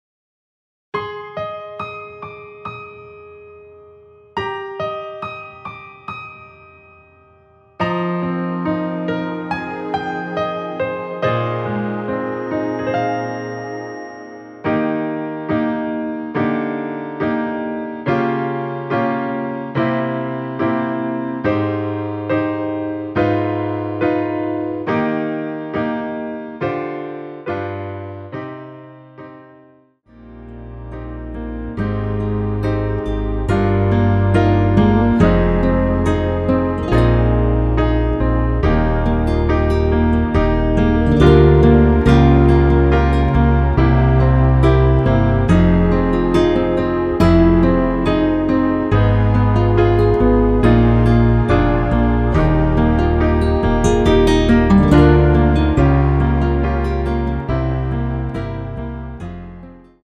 원키에서(+5)올린 여성분이 부르실수 있는 키로 제작 하였습니다.(미리듣기 참조)
Eb
앞부분30초, 뒷부분30초씩 편집해서 올려 드리고 있습니다.
중간에 음이 끈어지고 다시 나오는 이유는